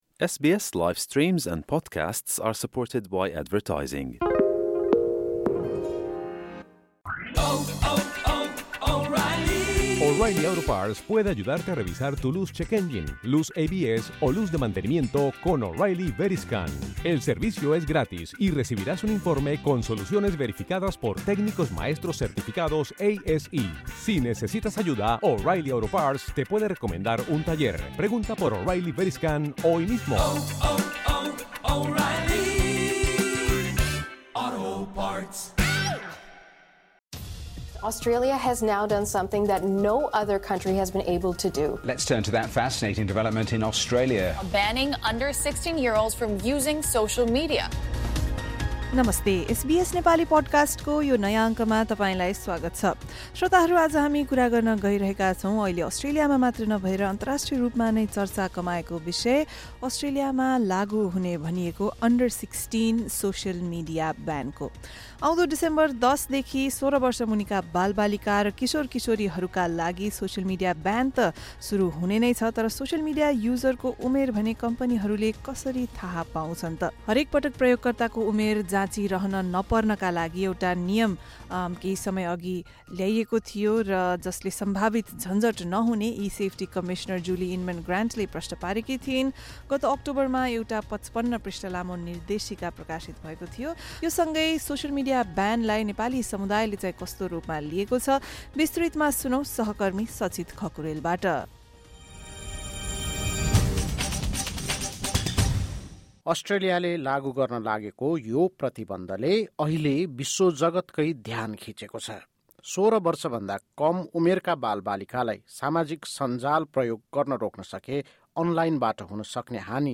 विश्वमै पहिलो भनिएको सोह्र वर्ष मुनिका बालबालिका र किशोर किशोरीहरूका लागि सोसल मिडिया ब्यान अस्ट्रेलियामा आउँदो डिसेम्बर १० देखि लागू हुँदै छ। यसको पालना नगर्ने कम्पनीहरू प्रति कस्तो कदम चालिन्छ र नेपाली समुदायले यो प्रतिबन्धलाई कस्तो रूपमा लिएका छन्? एक रिपोर्ट।